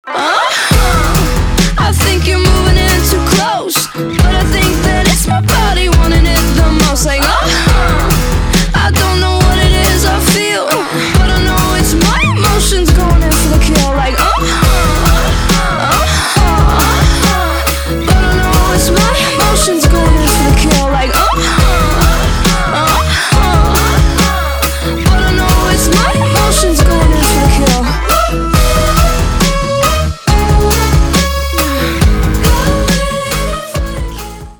• Качество: 320, Stereo
поп
женский голос